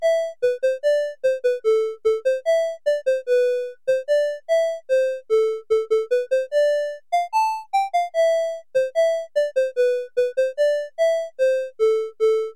I managed to tweak it to play the Tetris theme song.
My conversions were off by an octave, everything plays an octave lower than the original.